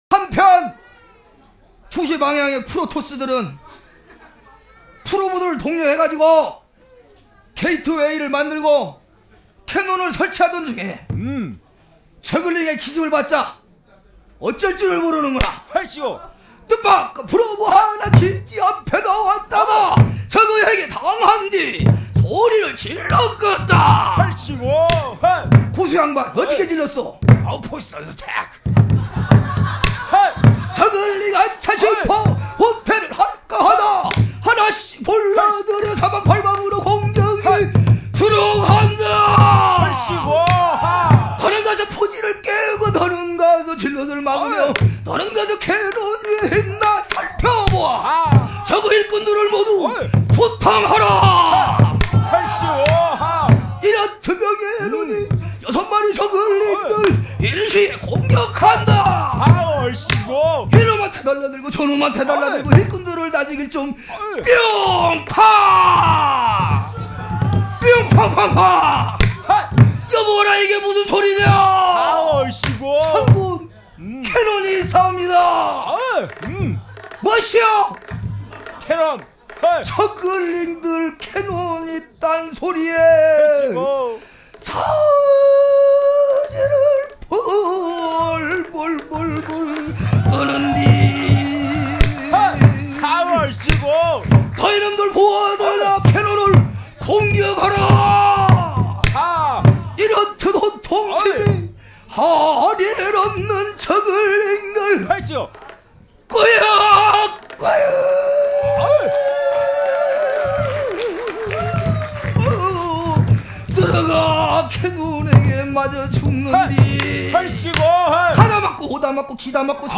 [7597] 국악 한 마당 - 플토 VC 저글링 대전
대중화에 앞장 서는 국악의 모습이 아름답습니다.